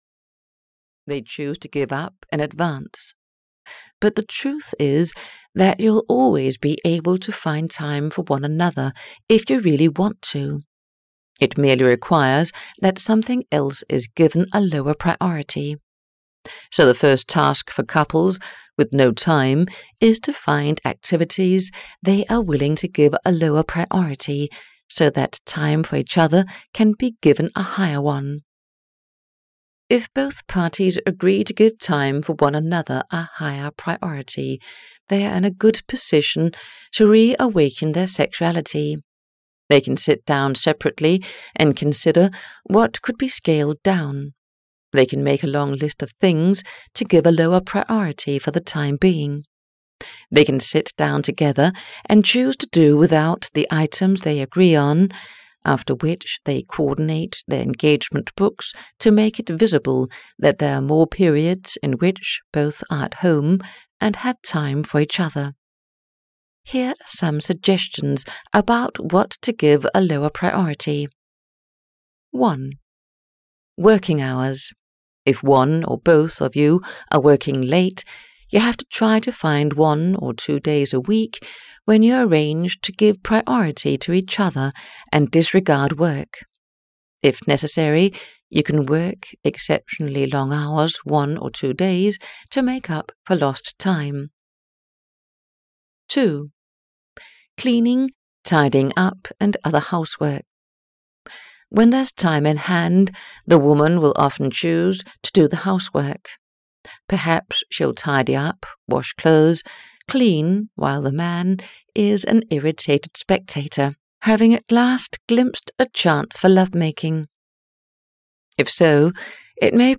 Not Tonight, Darling! When Sexual Desire Disappears (EN) audiokniha
Ukázka z knihy